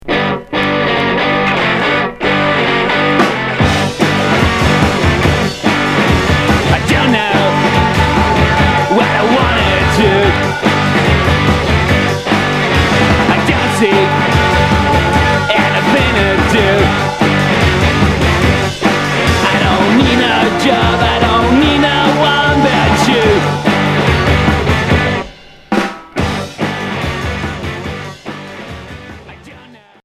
Garage punk Deuxième 45t retour à l'accueil